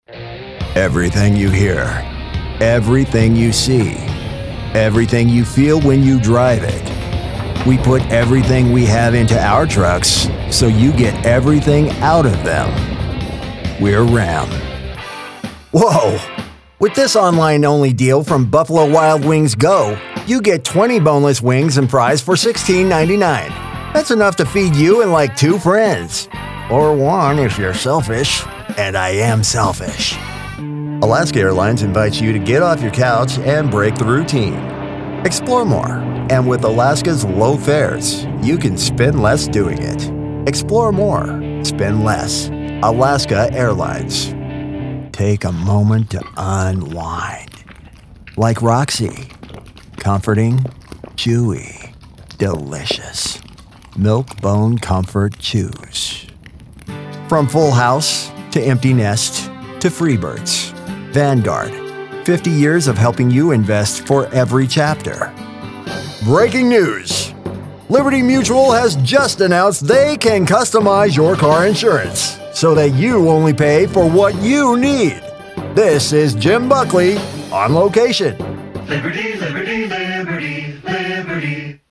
Male Voice Actor | Confident, Trustworthy, Conversational | Commercial & Corporate Specialist
I’m a male voice actor with a confident, trustworthy, and conversational sound, specializing in commercial, promo, corporate narration.